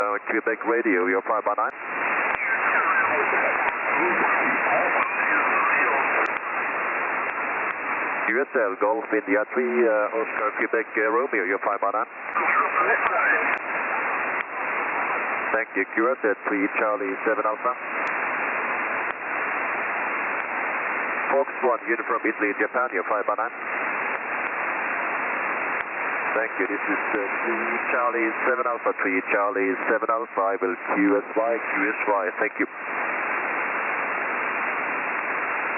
3C7A - Equatorial Guinea on 21MHz SSB